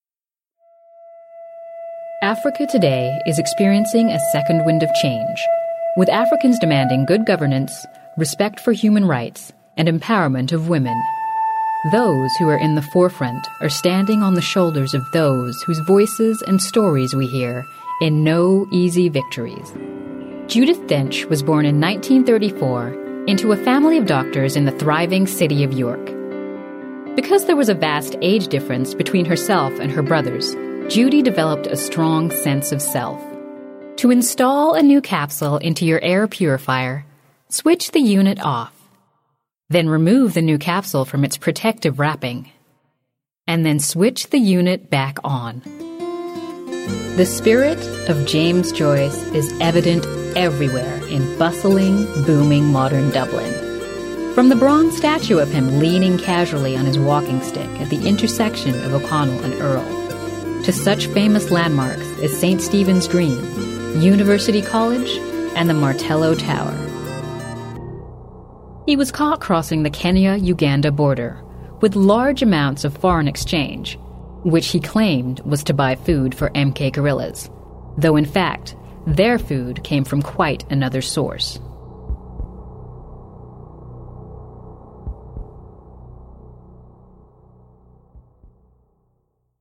Narration Demo
Middle Aged
My warm and confident delivery ensures your message resonates with your audience.